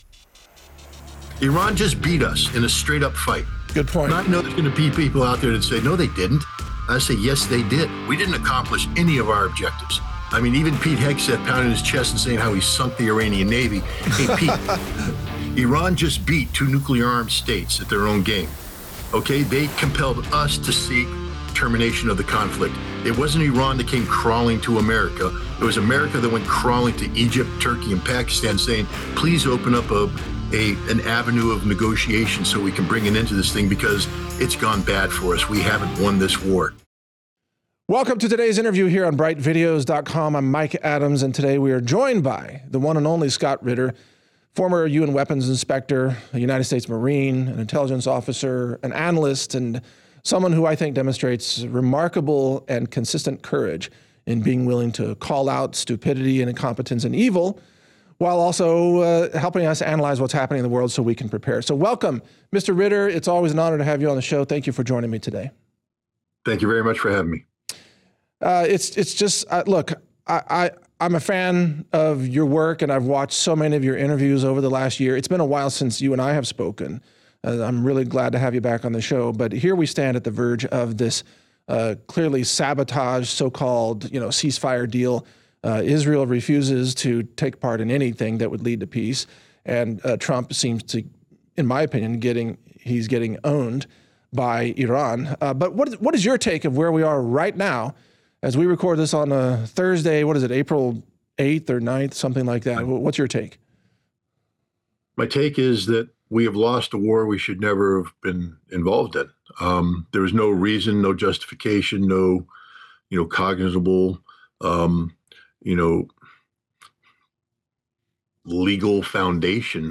Scott Ritter Interview: Illegal War on Iran & Collapse of U.S. Power - Natural News Radio